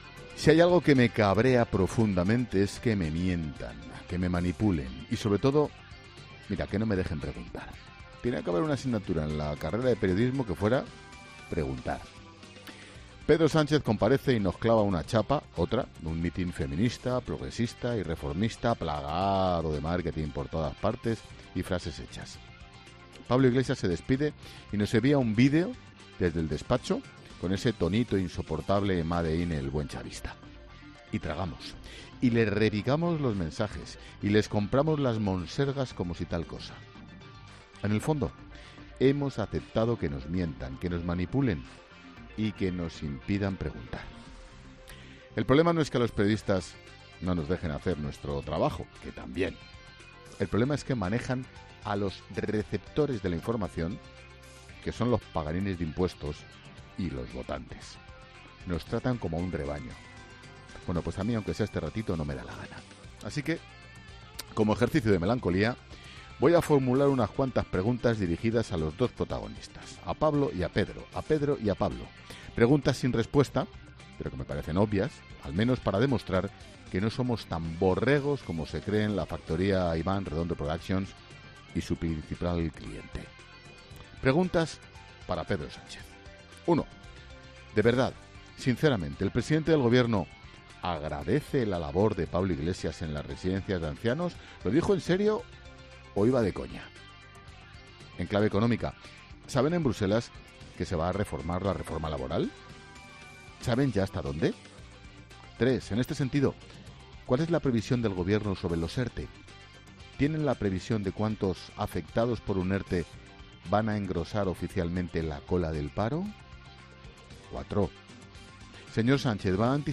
Monólogo de Expósito
El director de 'La Linterna', Ángel Expósito, da las principales claves informativas y analiza este 31 de marzo